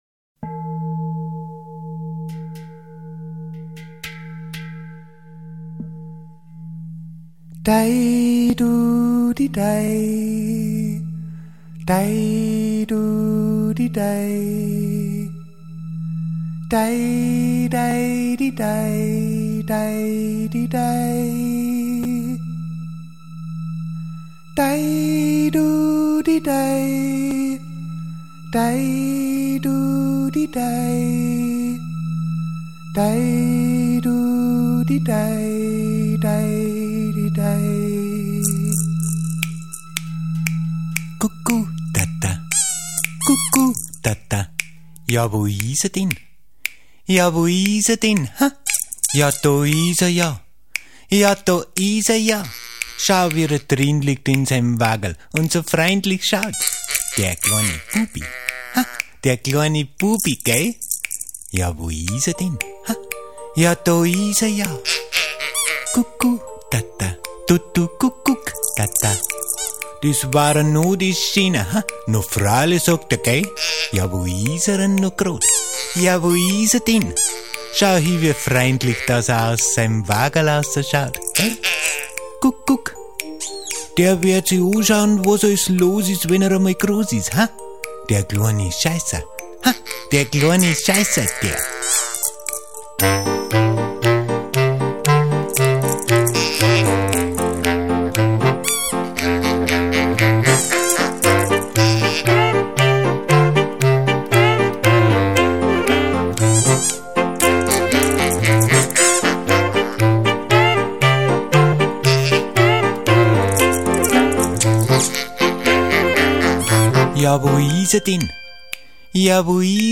die stilmäßig der Neuen Volksmusik zuzurechnen ist.